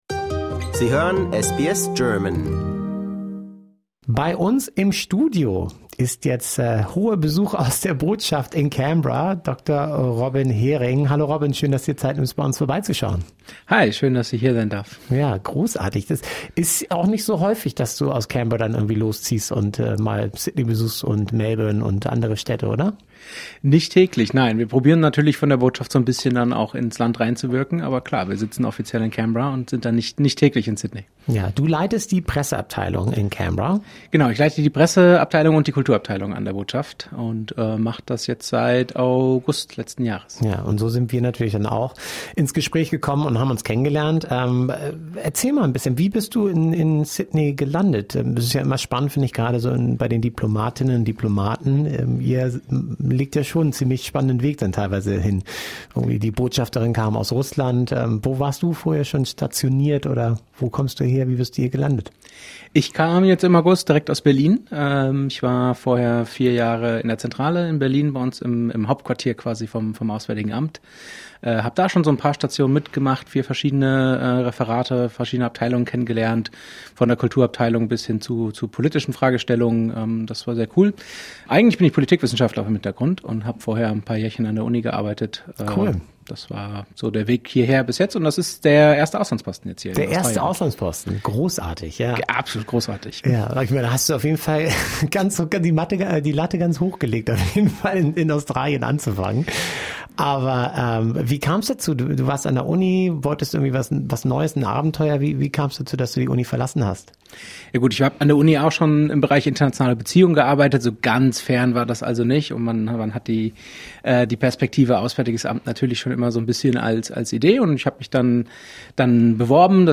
Im Gespräch bei SBS German